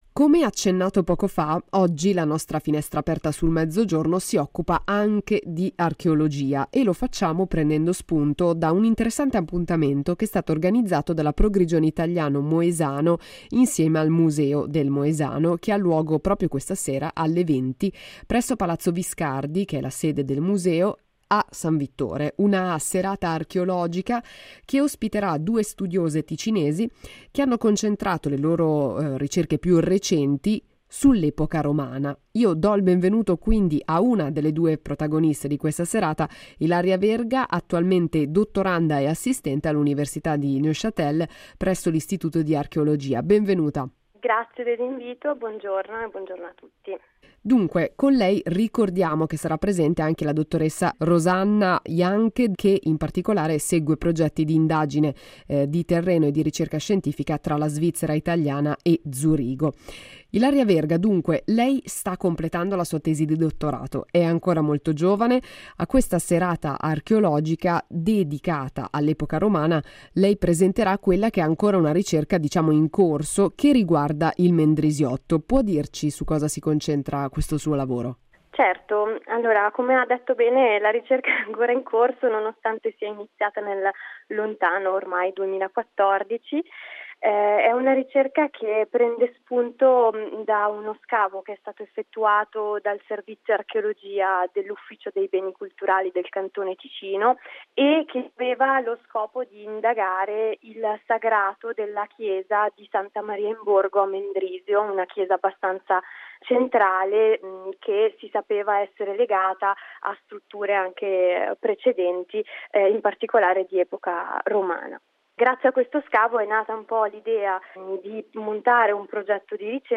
nostra ospite al telefono